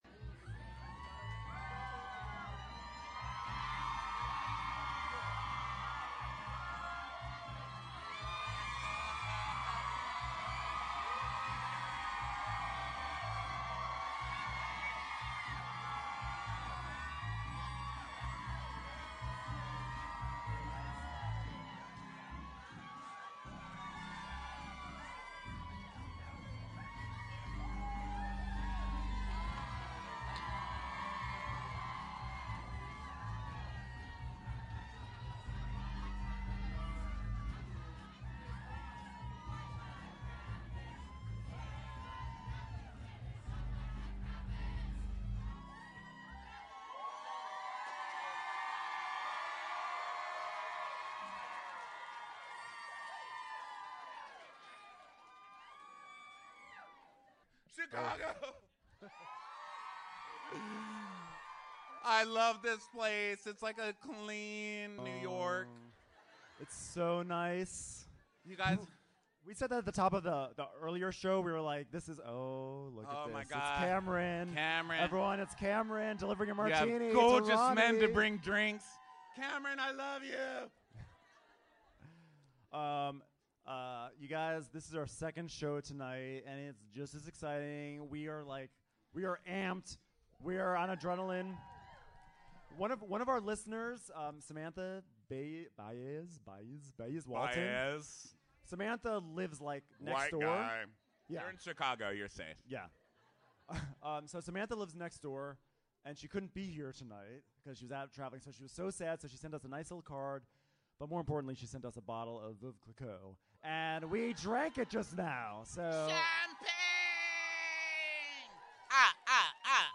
It got pretty rowdy at our Lincoln Hall late show, and we loved every second! LeeAnne Locken joined us onstage again and brought along Below Deck’s Nico Scholly.